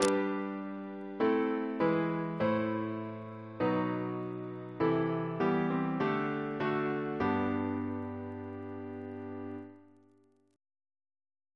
Single chant in G Composer: Henry Aldrich (1647-1710) Reference psalters: ACB: 62; H1940: 675; H1982: S13; PP/SNCB: 62; RSCM: 163